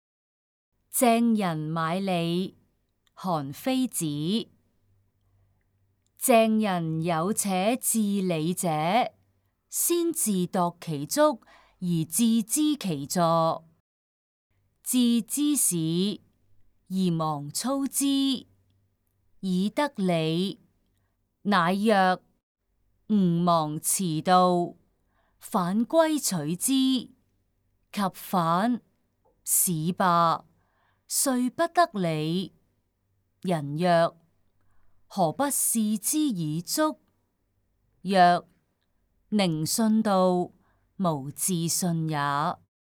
誦讀錄音